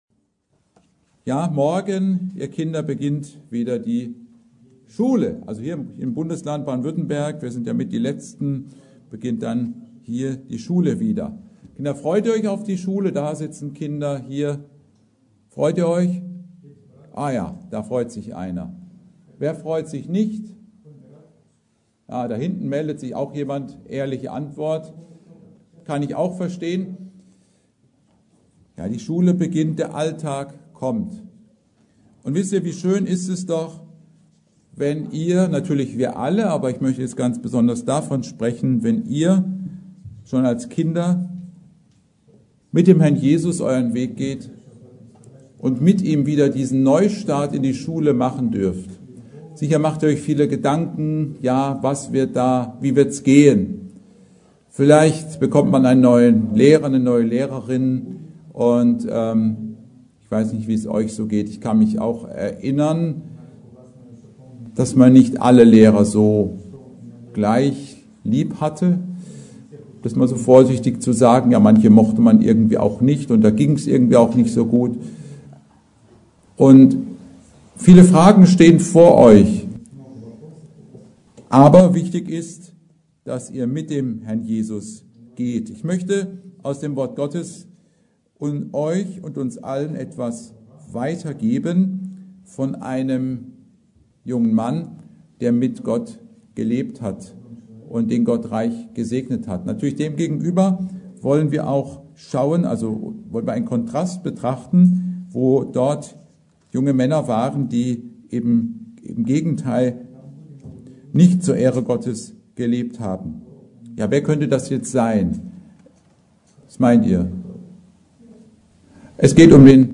Predigt: Samuel